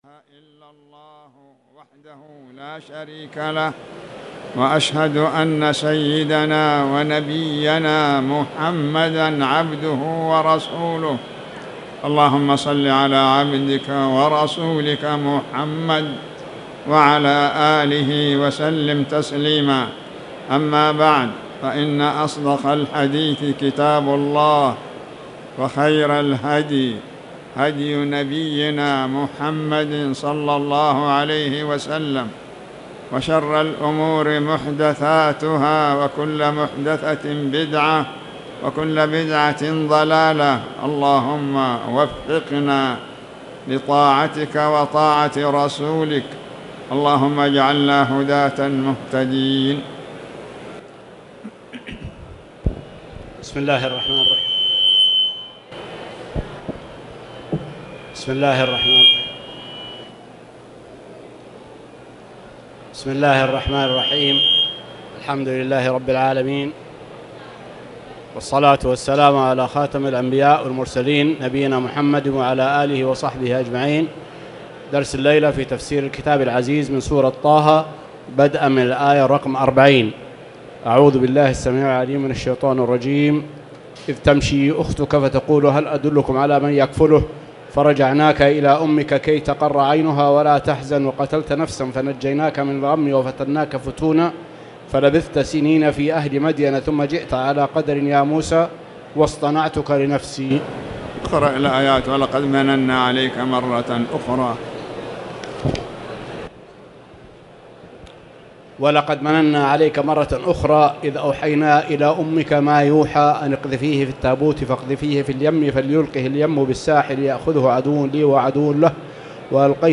تاريخ النشر ١٤ جمادى الآخرة ١٤٣٨ هـ المكان: المسجد الحرام الشيخ